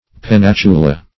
Pennatula \Pen*nat"u*la\, n.; pl. L. Pennatul[ae], E.